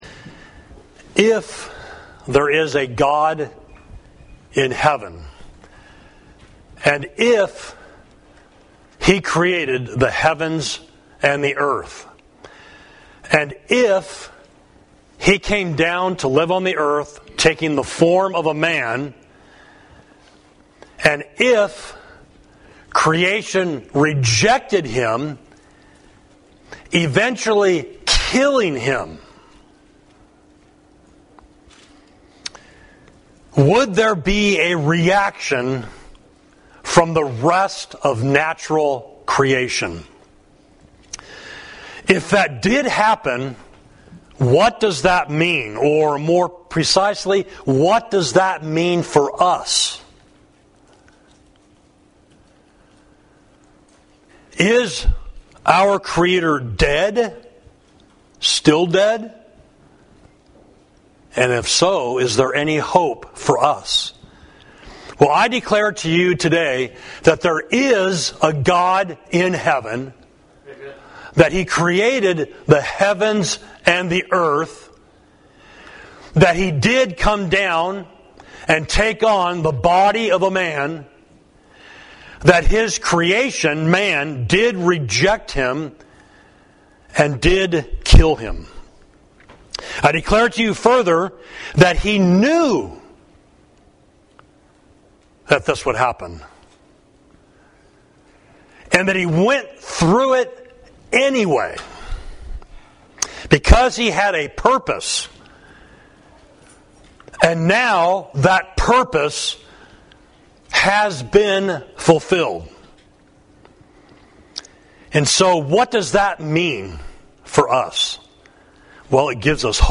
Sermon: When the Sun Refused to Shine, Luke 23.44–56